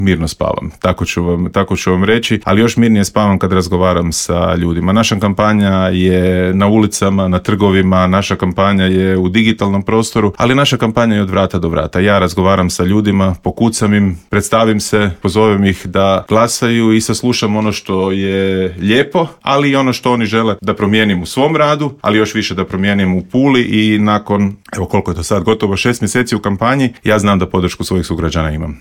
Borba se vodi i u Gradu Puli gdje poziciju čelnog čovjeka želi bivši predsjednik SDP-a i saborski zastupnik Peđa Grbin koji je u Intervjuu Media servisa poručio: